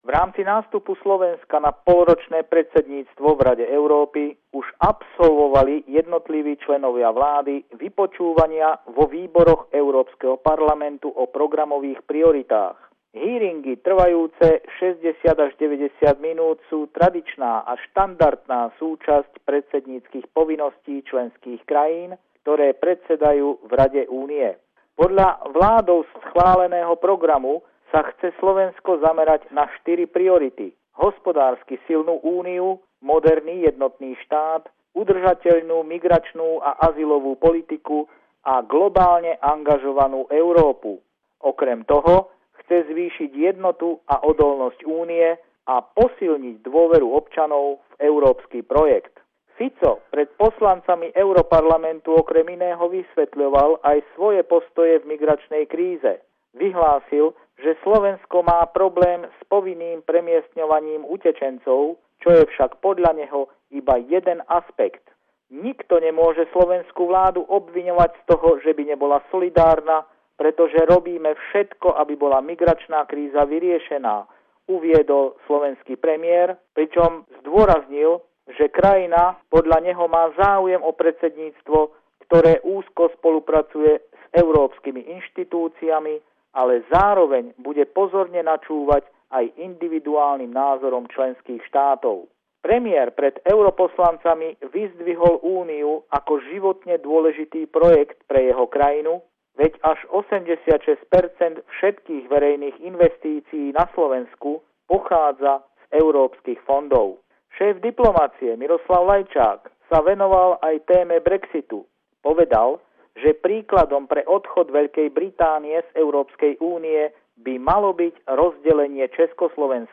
pravidelný telefonát týždňa od kolegu z Bratislavy